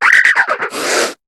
Cri de Manglouton dans Pokémon HOME.